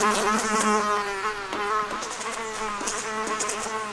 fly2.ogg